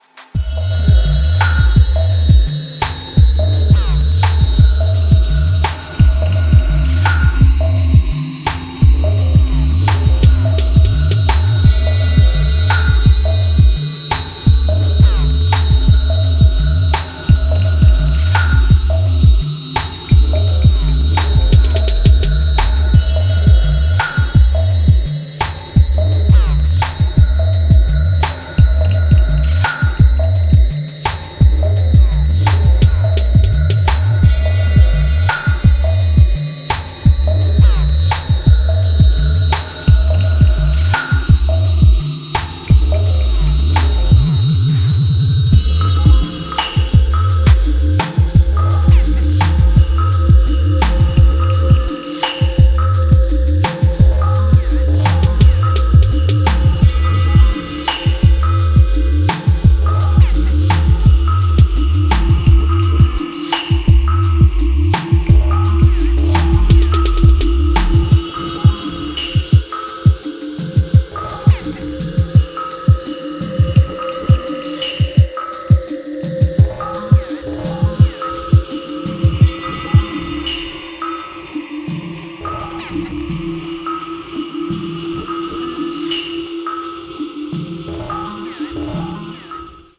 伝統的ダブ・テクノの感覚を現代的なスタイルに落とし込んだ感もあり、ジャンルを跨いだフロアで作用してくれるはず。